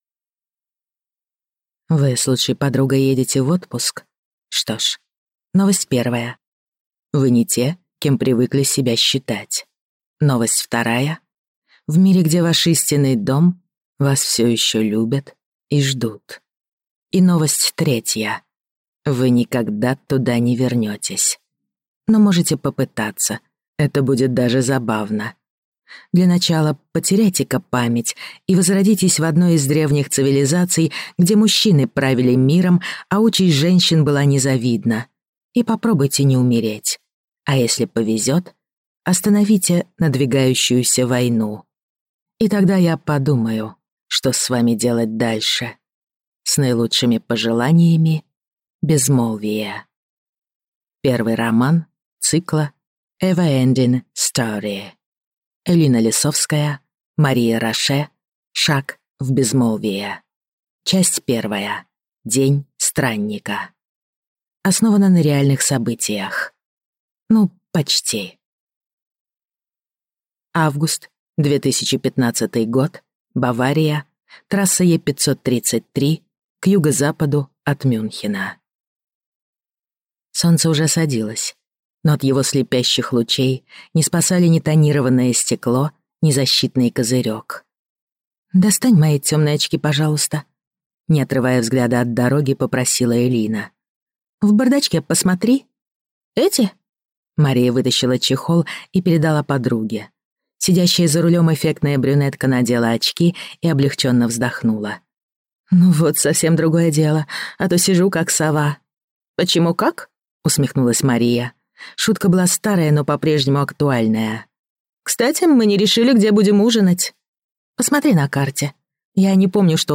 Аудиокнига Шаг в Безмолвие | Библиотека аудиокниг
Прослушать и бесплатно скачать фрагмент аудиокниги